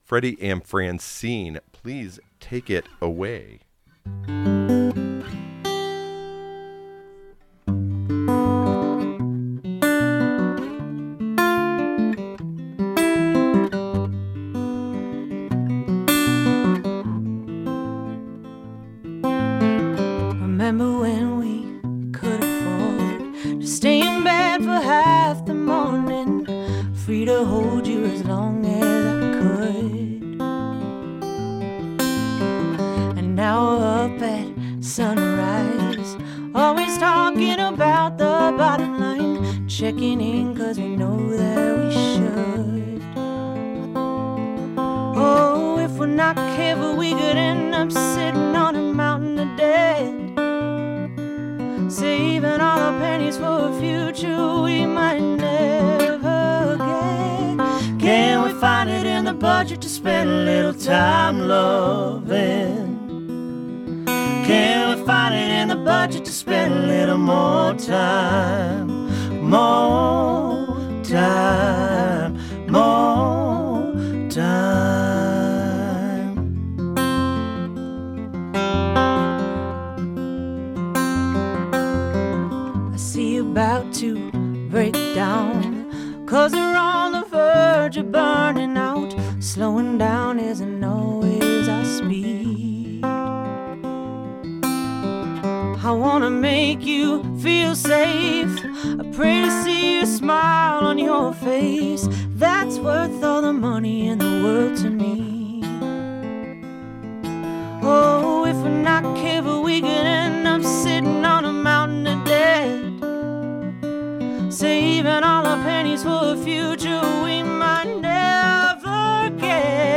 Listen to the live performance + interview here!
folk duo
soulful harmonies